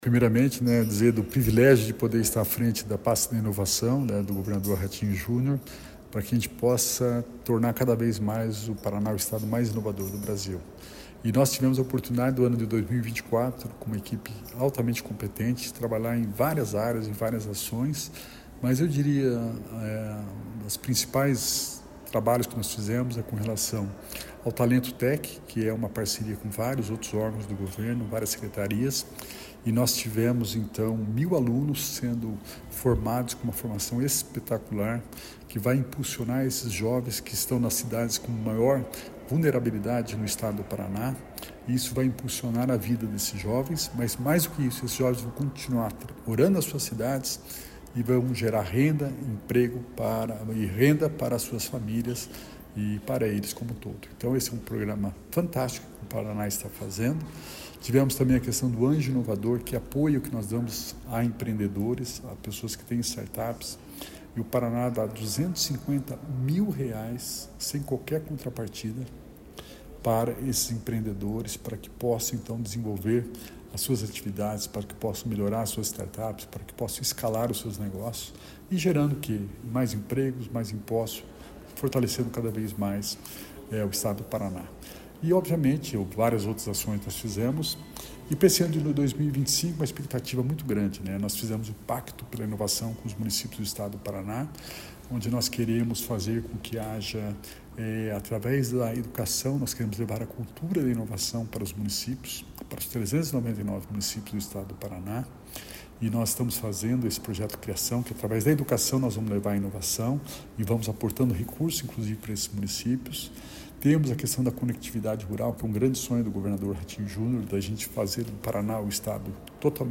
Sonora do secretário Estadual de Inovação, Alex Canziani, sobre o investimento em projetos estratégicos na área em 2024